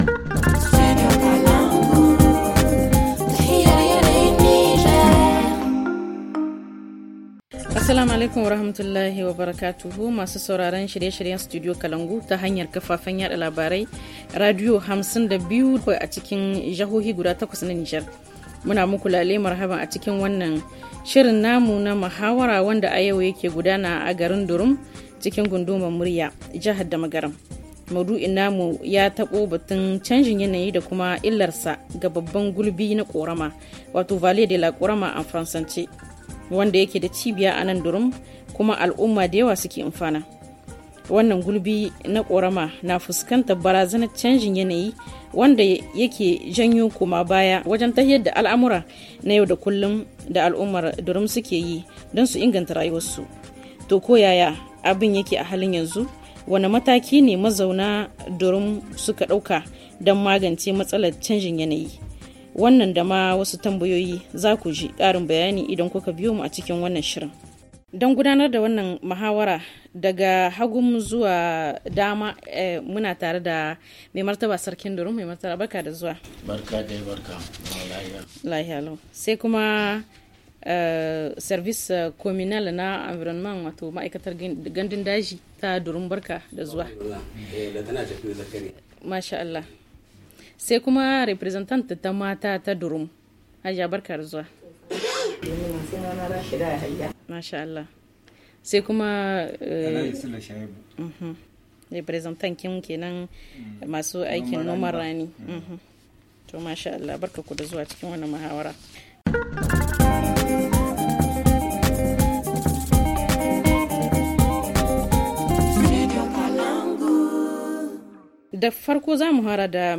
Le forum en haoussa